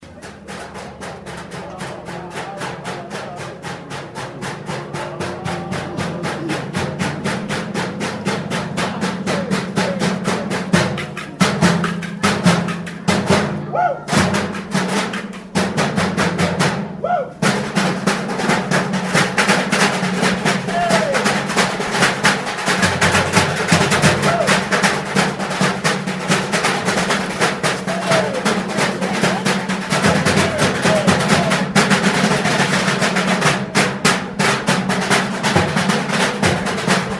They also play on bins ... and each other's helmets!